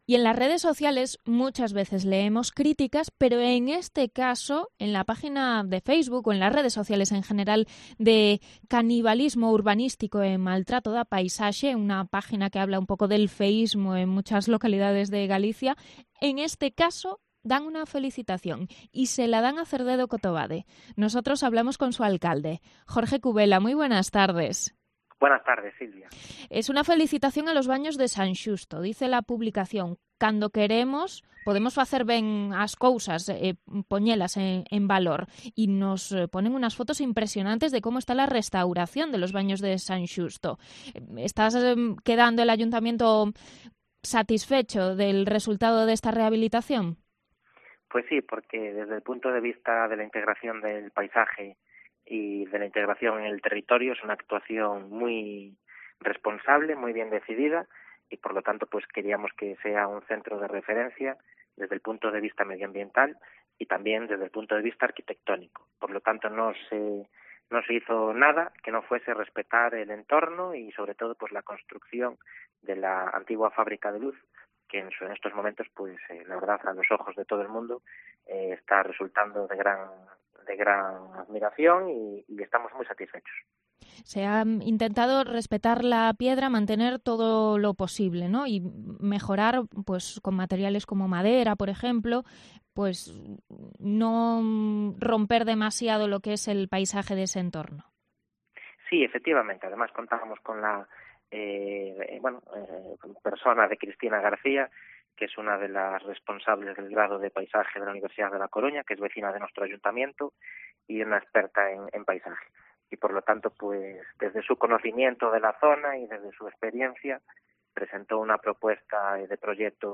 El alcalde de Cerdedo-Cotobade, Jorge Cubela, anuncia el hallazgo de agua termal en San Xusto